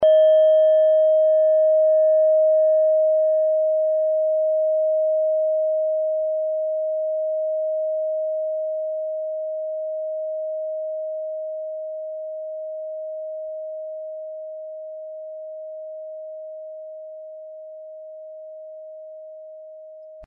Klangschale Nepal Nr.36
Wasserstoffgamma Frequenz
klangschale-nepal-36.mp3